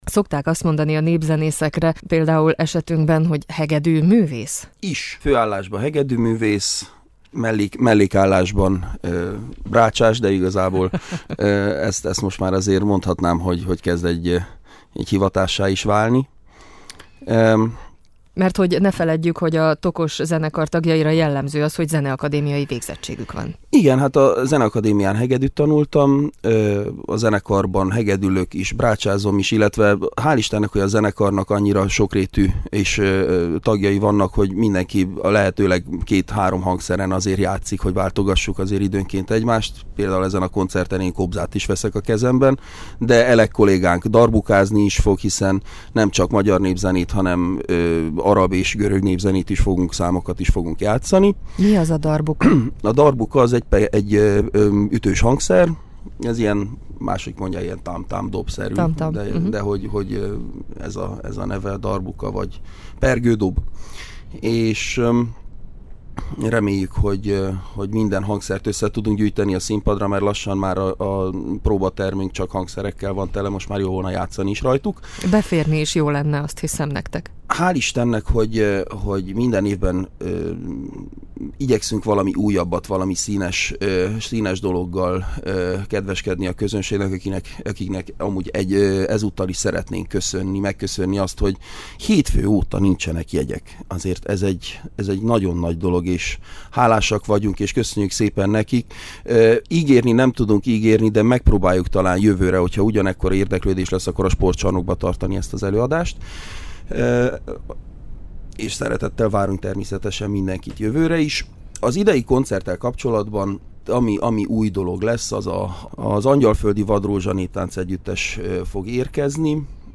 A stúdióban